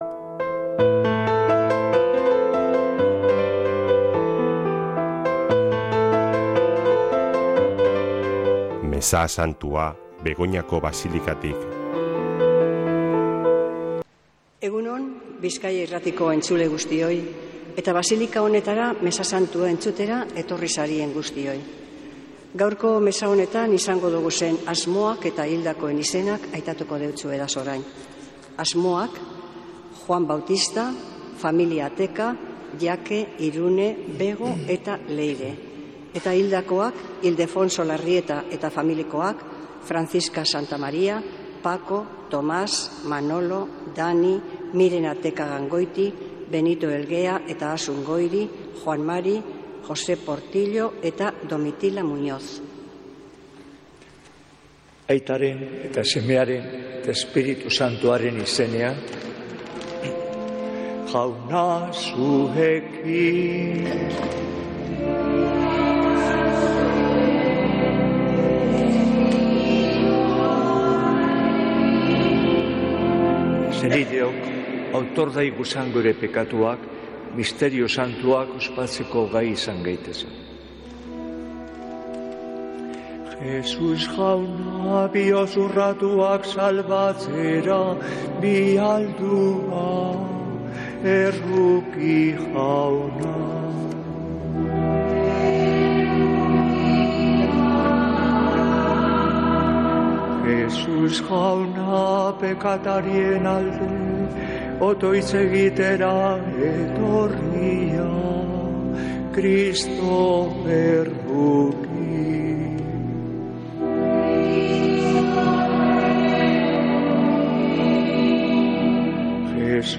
Mezea (24-11-12)